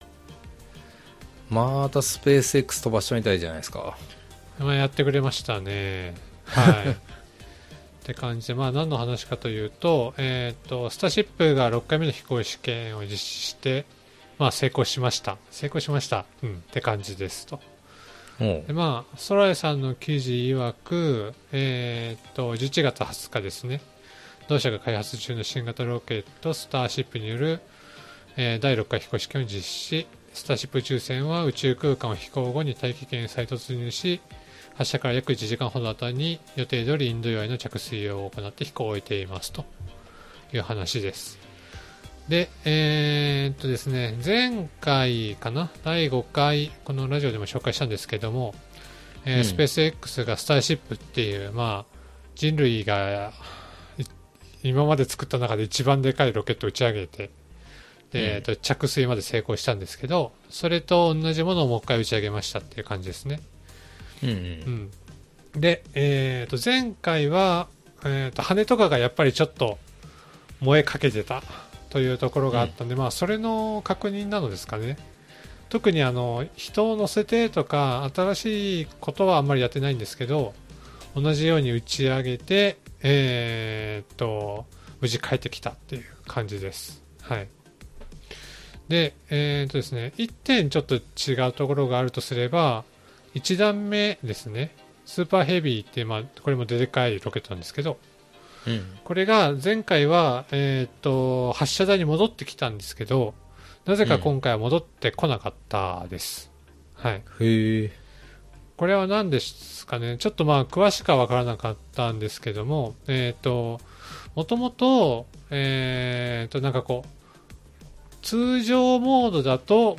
(Bilingual)